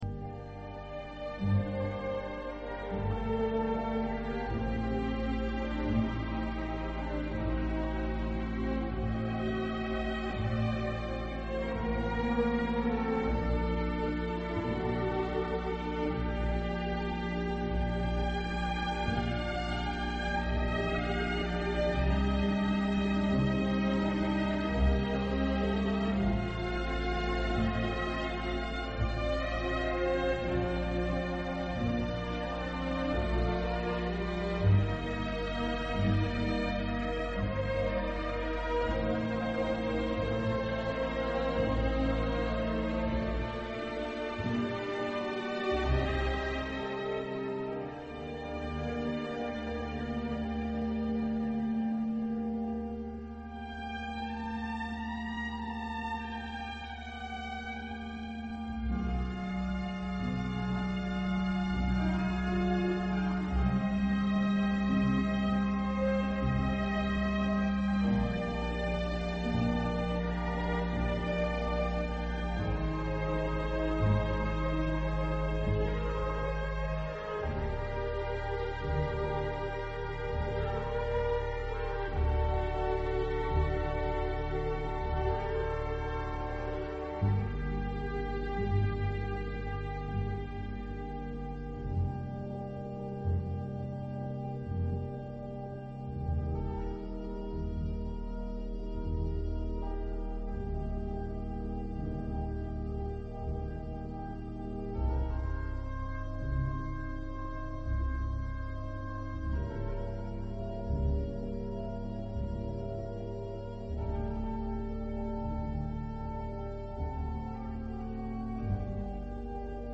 Música: Adágio – Albinoni